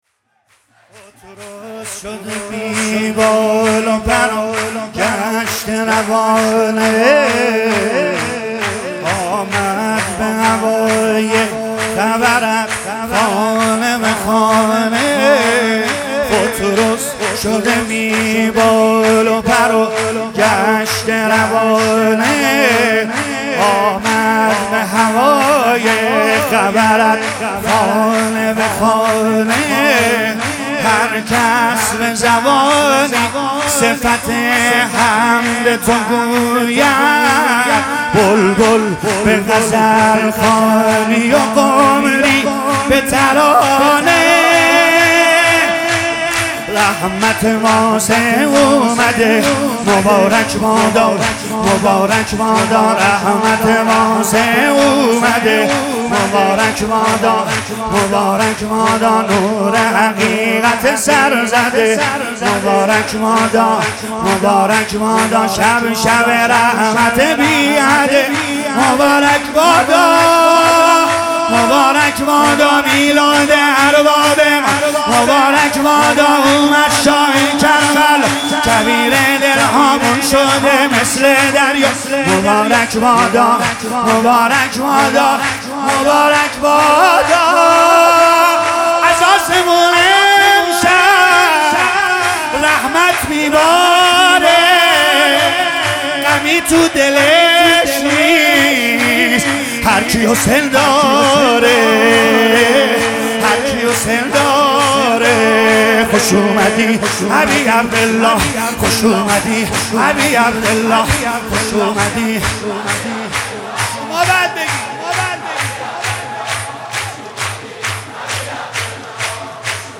♦ مراسم جشن میلاد سرداران کربلا
🔊 بخش چهارم سرود | هی میگم سلام حسین اومده آقام حسین